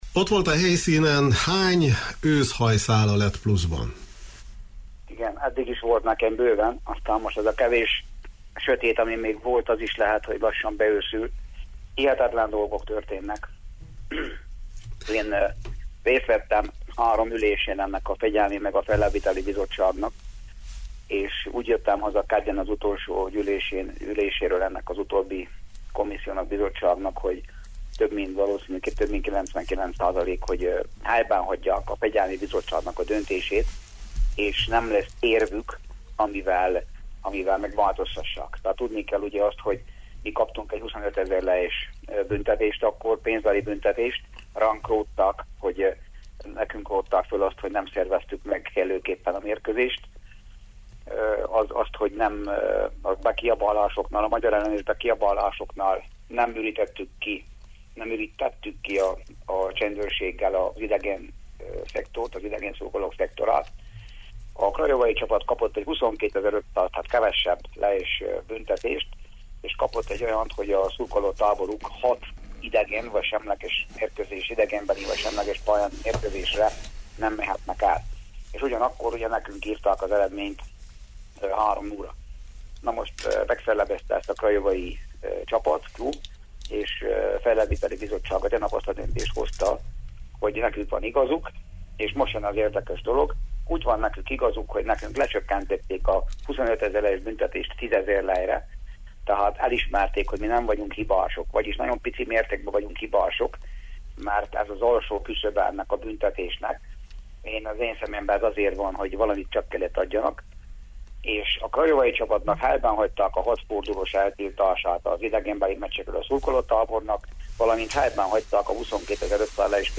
Ő értékelte a határozatot, majd a stúdióban ülő Novák Károly Eduárd sportminiszter is elmondta véleményét a kialakult precedensértékű döntésről: